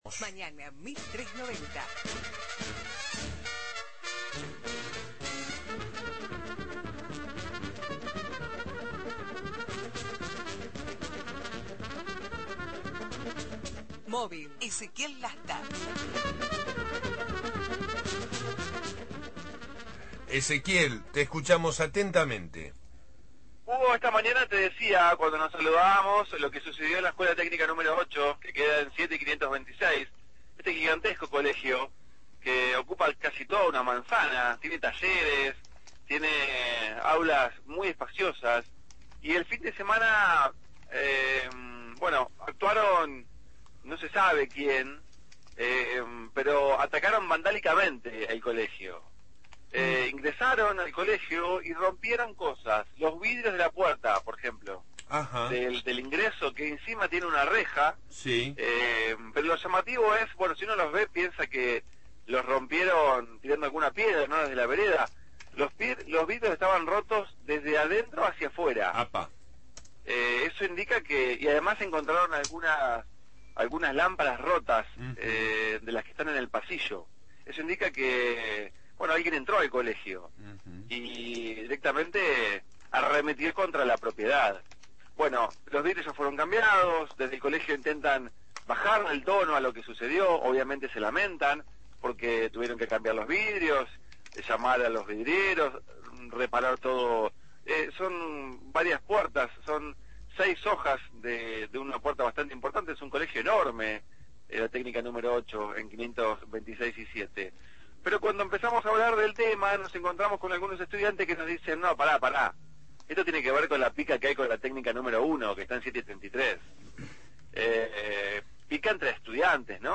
Móvil/ Ataque vandálico a la Escuela Técnica nº8 – Radio Universidad